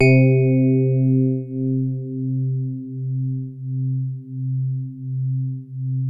FINE HARD C2.wav